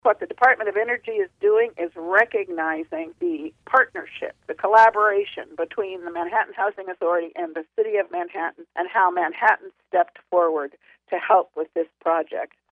While the recognition emphasizes the energy-efficiency aspect of the project, Manhattan city commissioner Linda Morse explains that there is more to it than that.